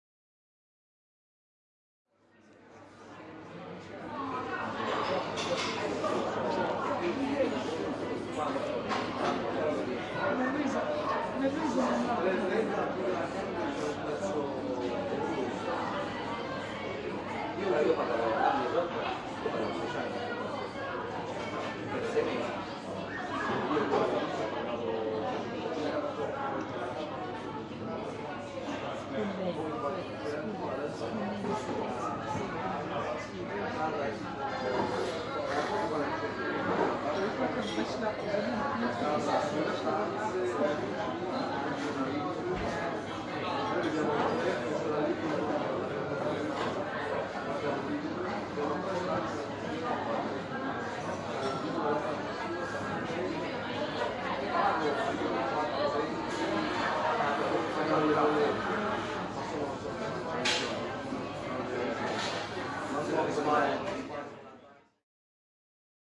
Download Coffee Shop sound effect for free.
Coffee Shop